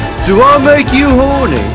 horn.wav